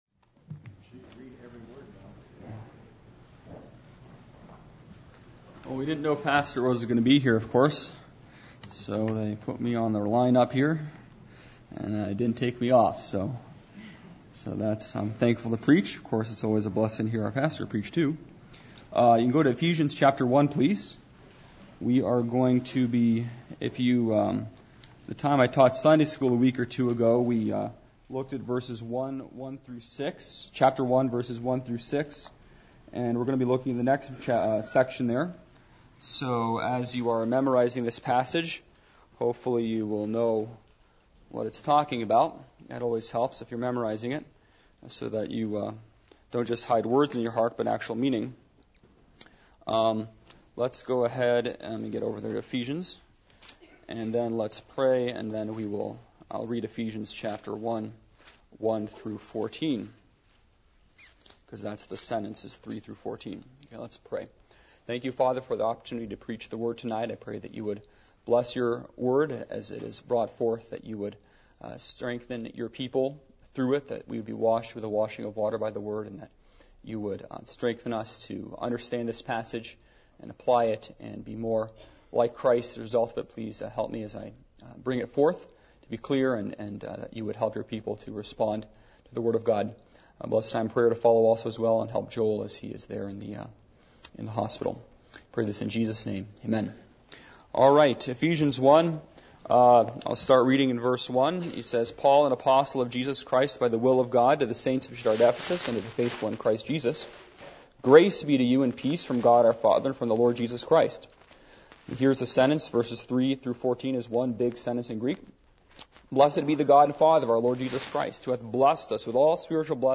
Passage: Ephesians 1:1-10 Service Type: Midweek Meeting %todo_render% « The New Testament Church The Attributes Of God